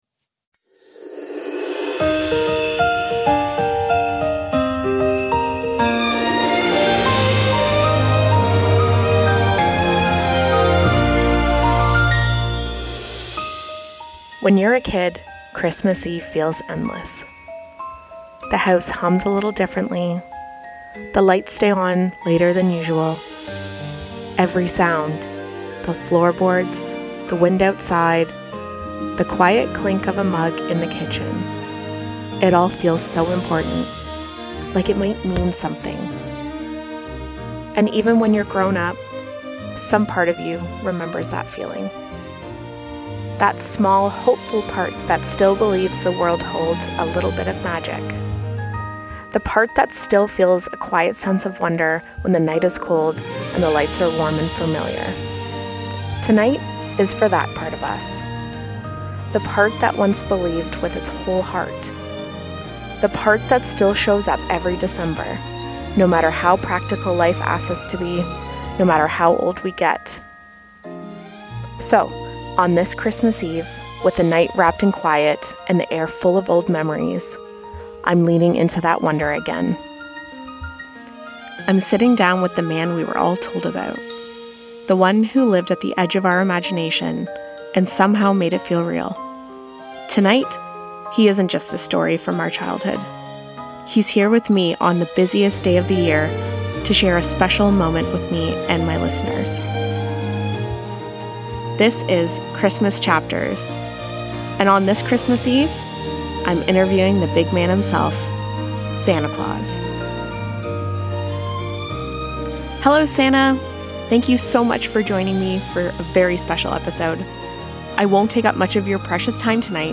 In this special episode of Christmas Chapters, I sit down with Santa Claus on his busiest night of the year. Together, we explore what makes Christmas timeless, why it sparks joy and playfulness, and the simple, important truths we sometimes forget.